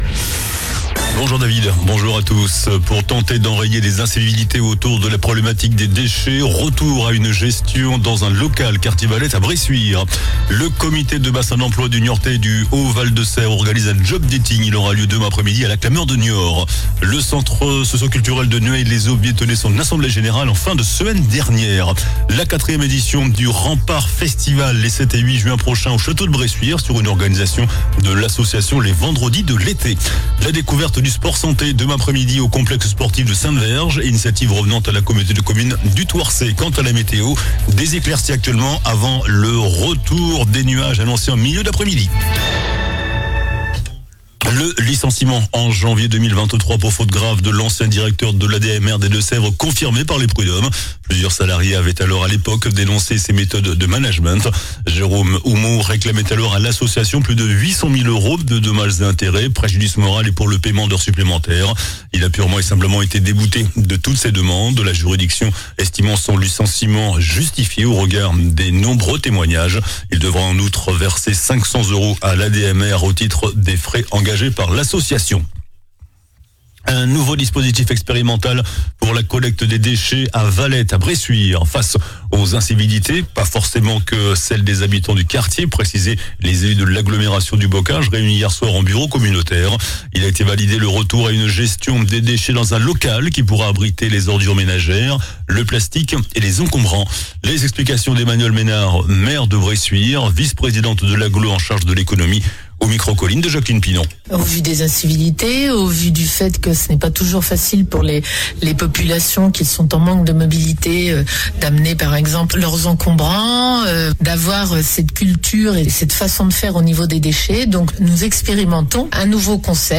JOURNAL DU MERCREDI 16 AVRIL ( MIDI )